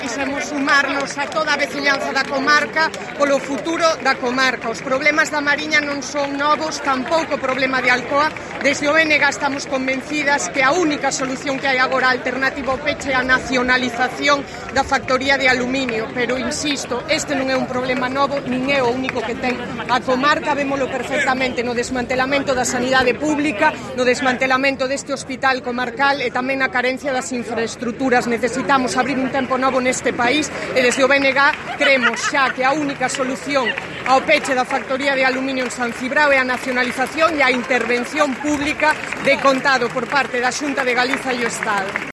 Declaraciones de OLALLA RODIL, diputada y candidata del BNG por Lugo